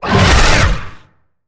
Sfx_creature_squidshark_chase_os_01.ogg